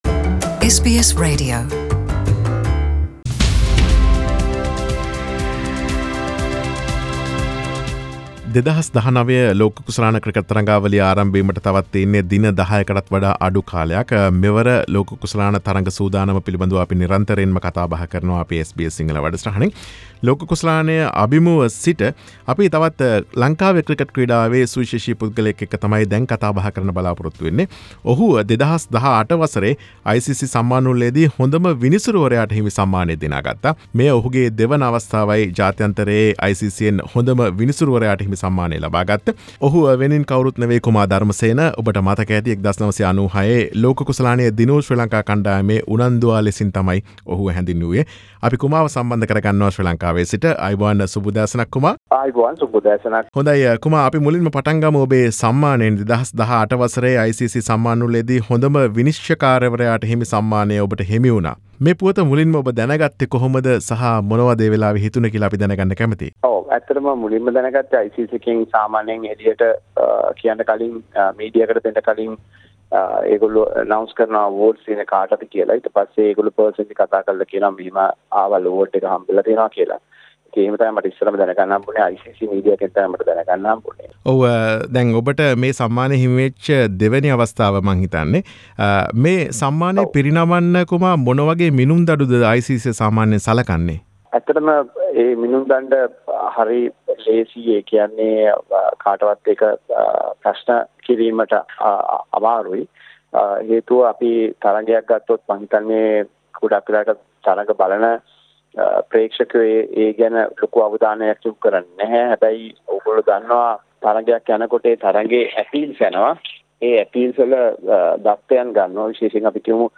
ICC Umpire of the year, Kumar Dharmasena speaks to SBS Sinhalese.
Sri Lankan umpire Mr. Kumar Dharmasena has won the David Shepherd Trophy for ICC Umpire of the Year 2018. SBS Sinhalese discuss with Mr. Kumar Dharmasena about his award and his cricket & umpire careers.